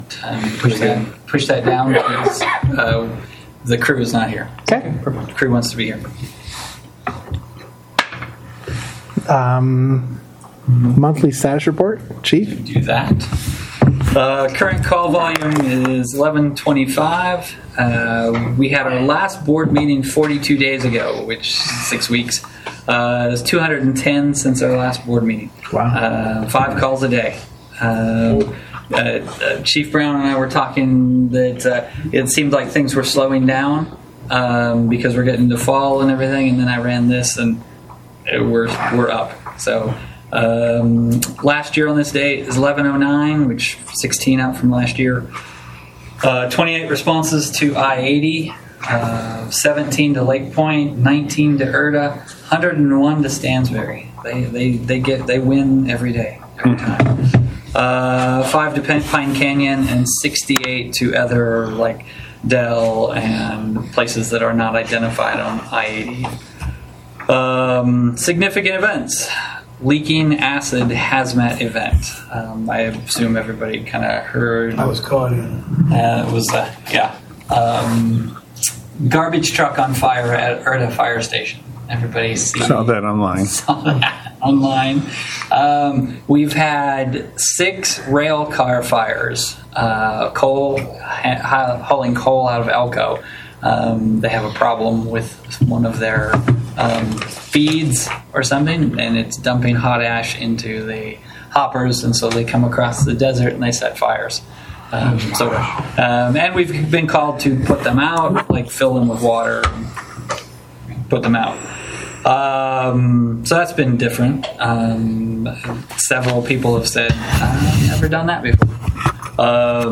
Board Meeting
Notice is hereby given that the North Tooele Fire Protection Service District will hold a board meeting on October 15, 2025, at 6:30 p.m. at the Stansbury Park Fire Station, 179 Country Club, Stansbury Park, UT.